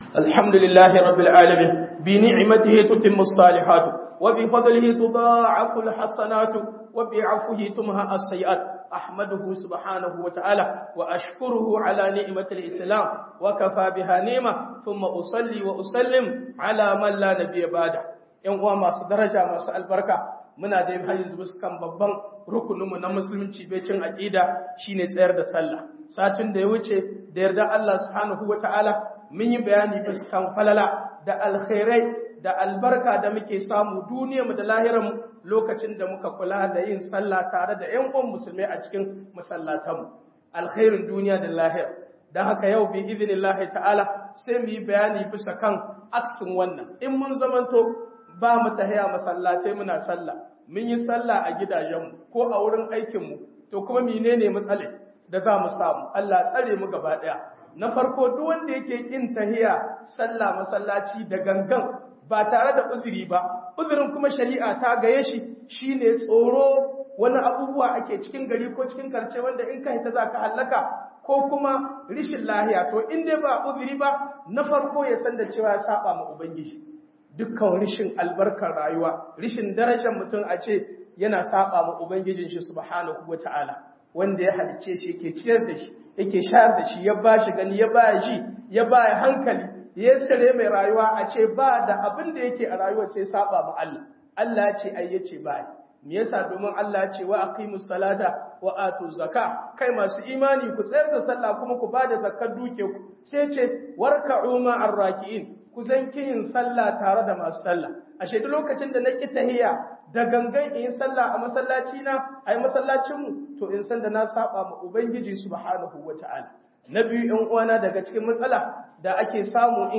hudubar Juma'a masalacin Morgane 15 Fev 2025